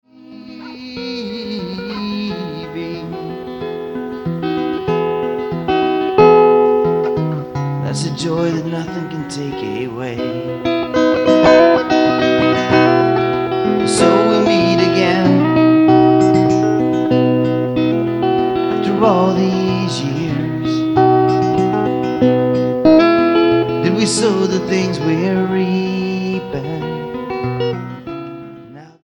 STYLE: Roots/Acoustic
Live